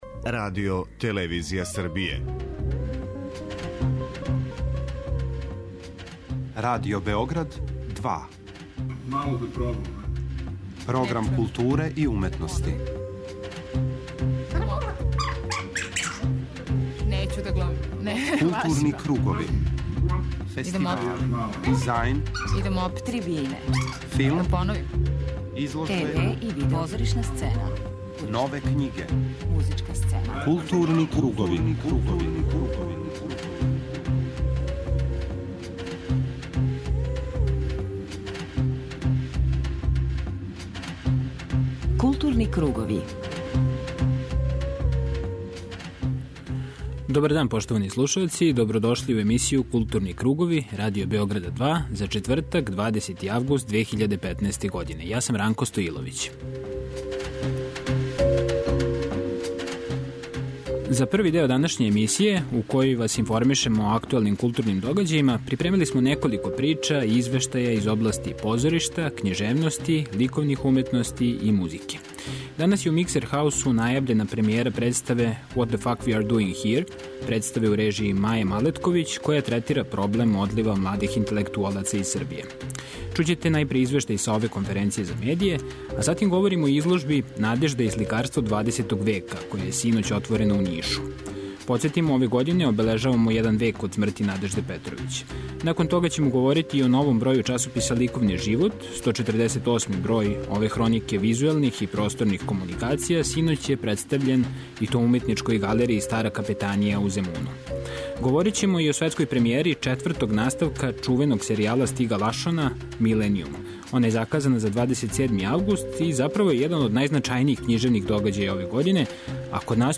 У тематском блоку слушаћете два разговора емитована током позоришне сезоне за нама.